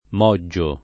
moggio [ m 0JJ o ]